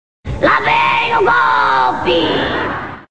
Personagem Maria Santa de A Praça É Nossa solta seu famoso bordão 'Lá Vem O Golpe!'